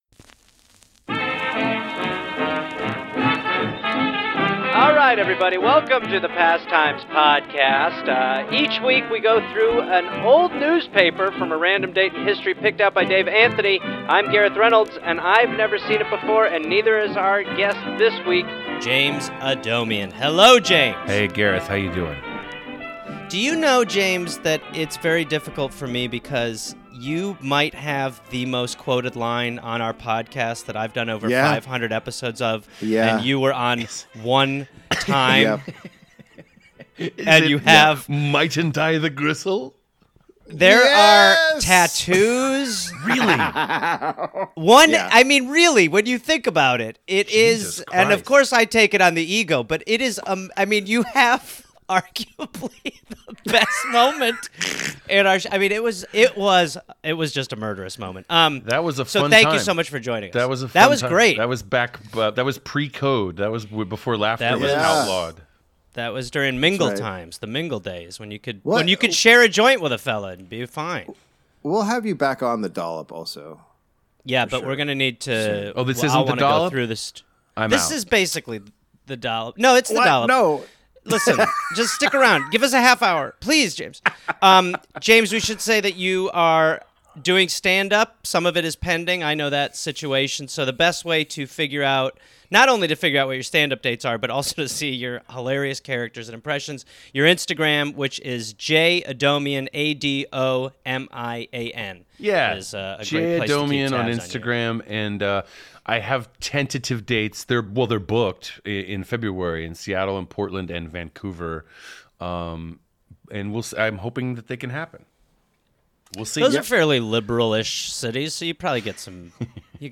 Holy hell that Alex Jones impression was too good
Seriously he sounds almost too much like him.